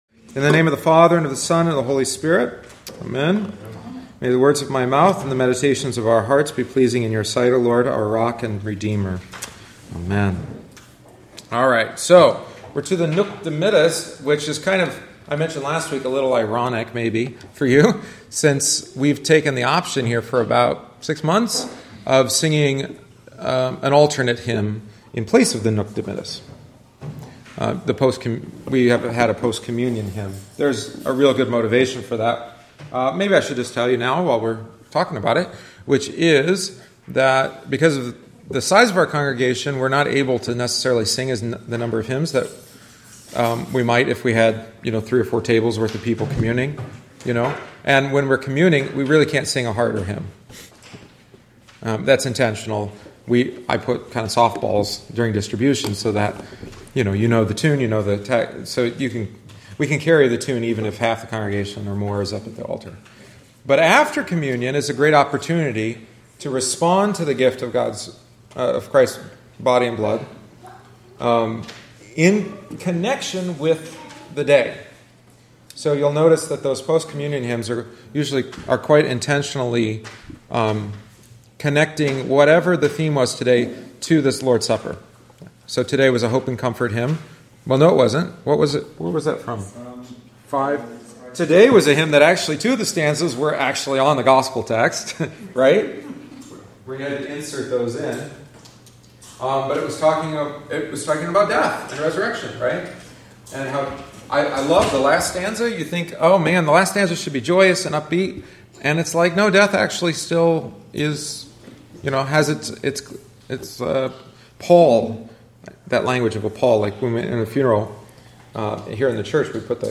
Join us each week after Divine Service (~10:30am) for coffee, treats, and a study of a part of the Liturgy.